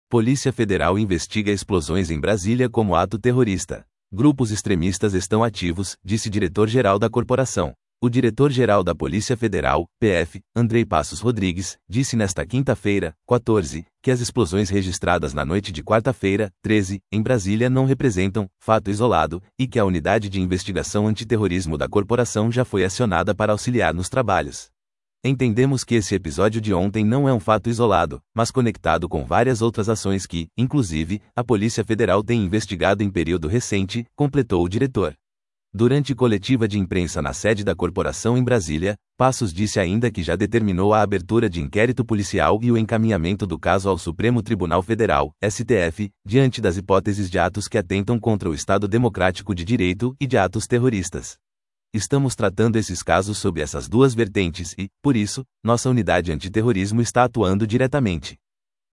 Durante coletiva de imprensa na sede da corporação em Brasília, Passos disse ainda que já determinou a abertura de inquérito policial e o encaminhamento do caso ao Supremo Tribunal Federal (STF) diante das hipóteses de atos que atentam contra o Estado Democrático de Direito e de atos terroristas.